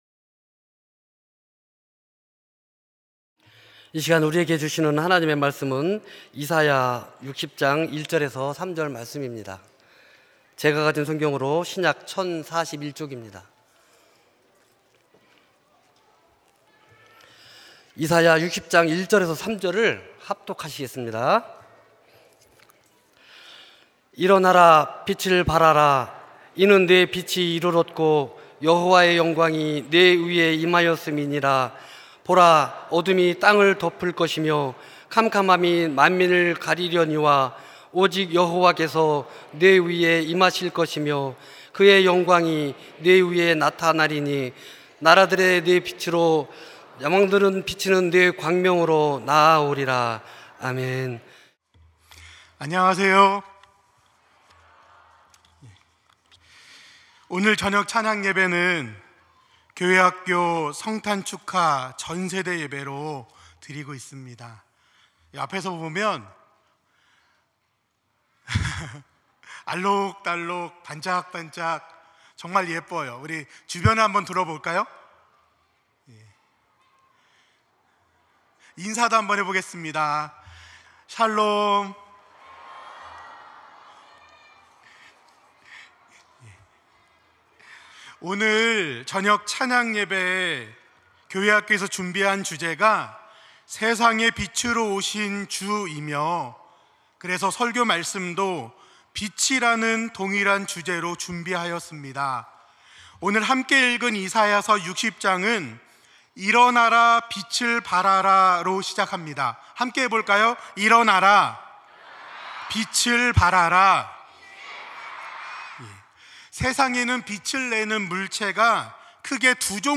찬양예배 - 빛을 발하라